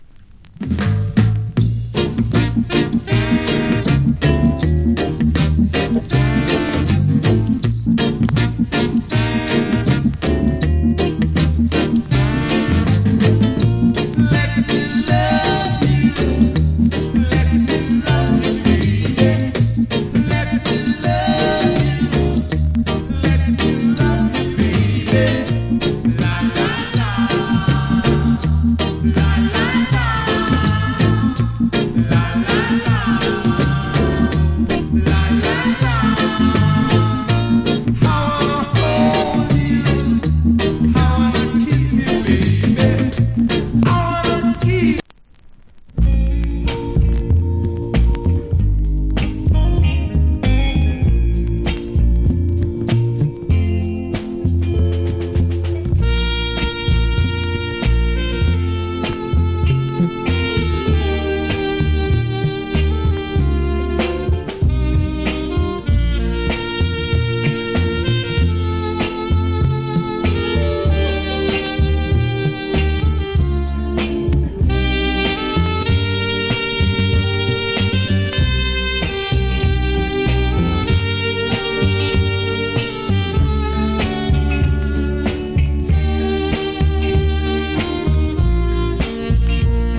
Great Rock Steady Vocal.